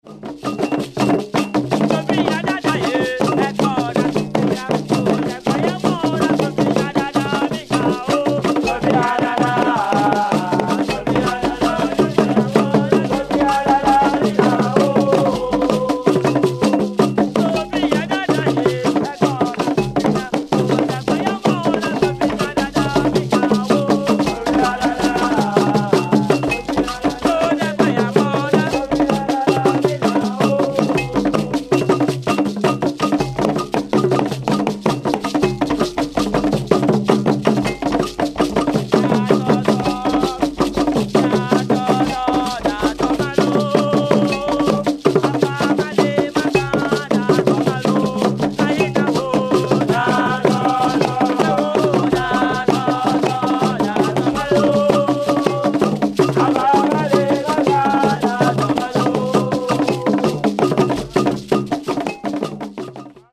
The ompeh drum is an open single-headed conical drum made from one single piece of wood.
This drum has a clear sound and plays ostinato figures and cross rhythms. The ompeh is played alternately with a wooden drumstick and the palms and accompanies traditional dances such as the ompeh dance of the Efutu.
ompeh-dance.mp3